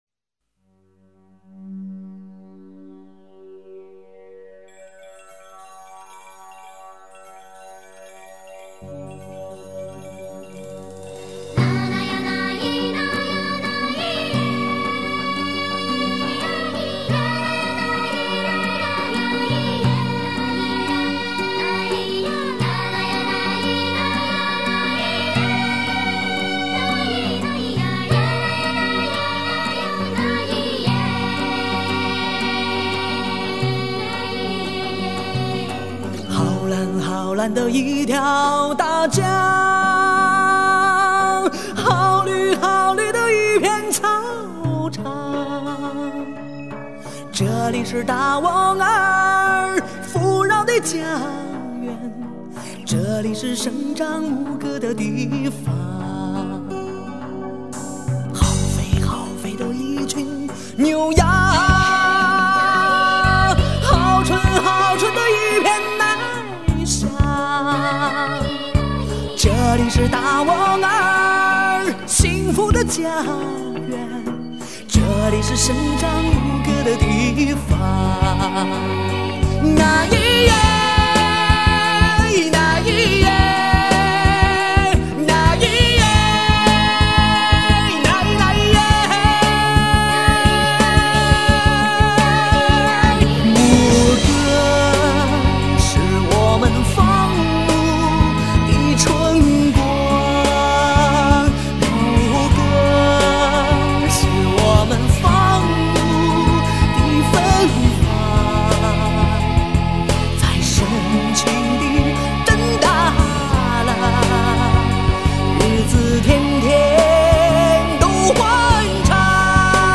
中国首张达斡尔族原创歌曲专辑
贝司
吉他